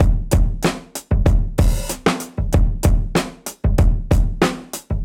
Index of /musicradar/dusty-funk-samples/Beats/95bpm
DF_BeatB_95-02.wav